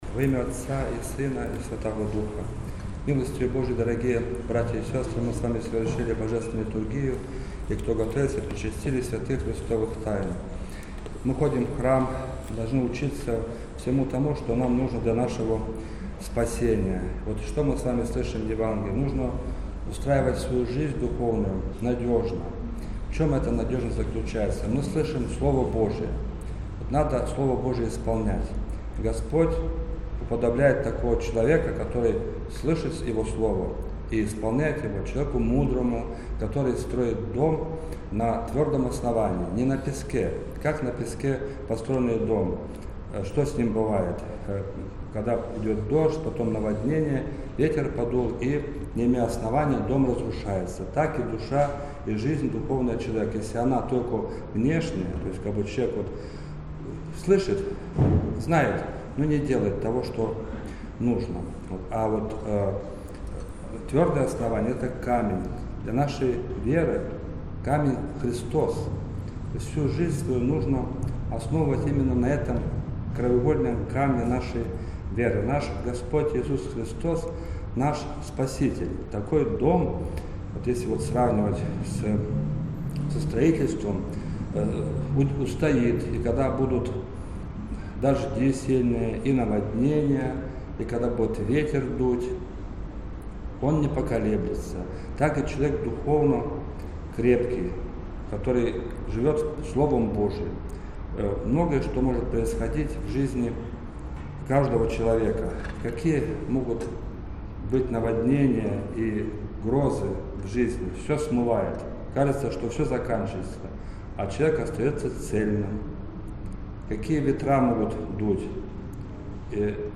Субботняя-проповедь.mp3